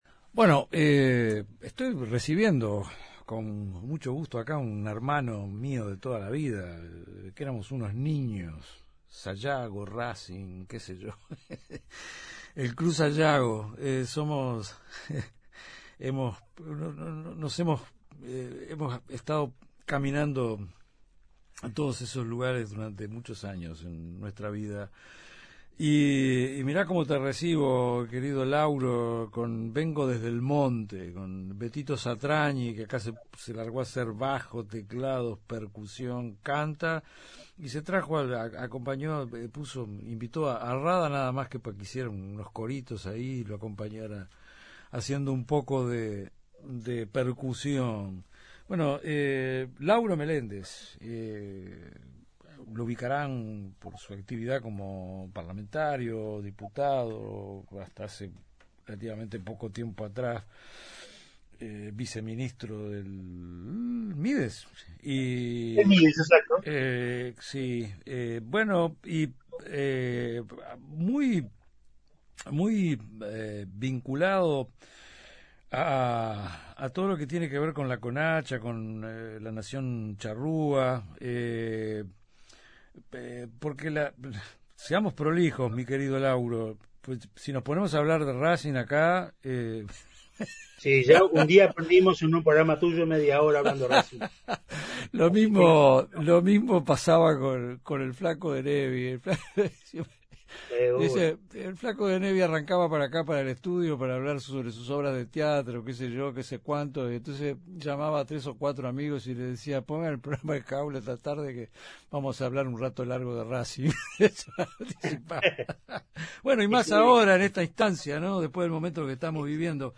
En el marco del Día de la Nación Charrúa y la Identidad Indígena, conversamos con Lauro Meléndez, integrante de ADENCH y ex subsecretario del Ministerio de Desarrollo Social